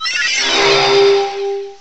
cry_not_cosmoem.aif